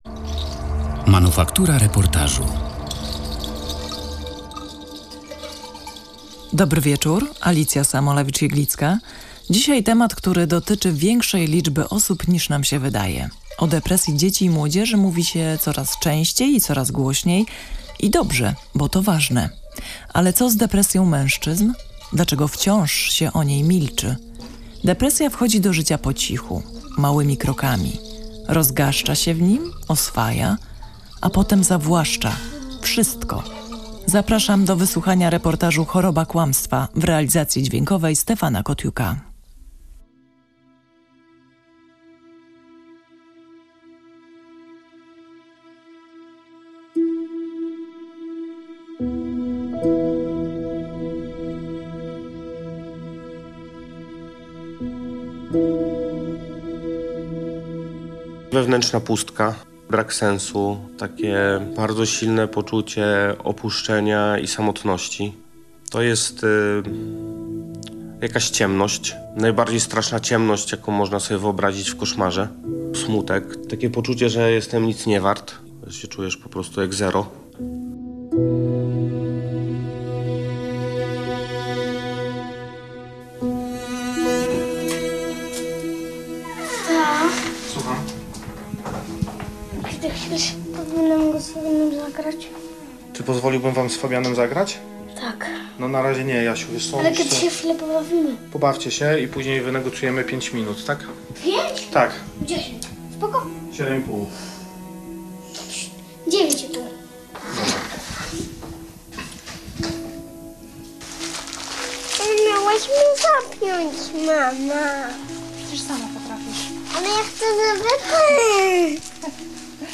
Reportaż „Choroba kłamstwa”, czyli zobaczyć niezauważalne